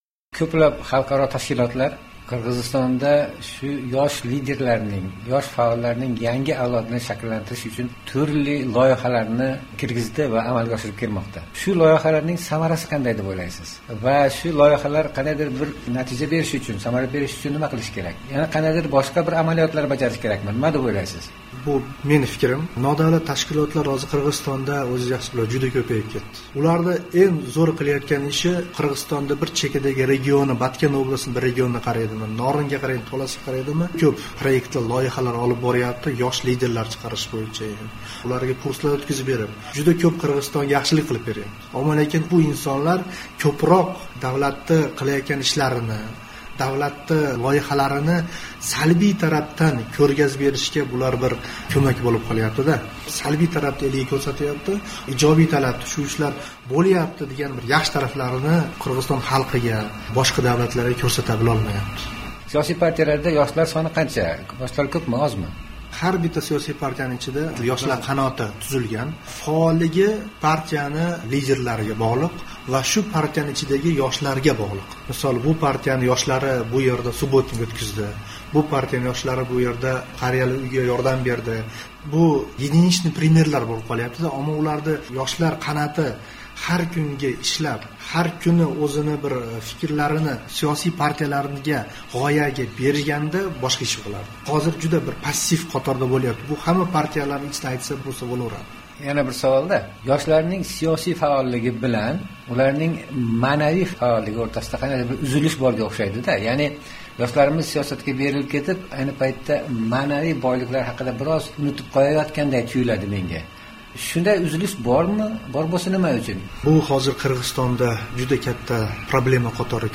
suhbat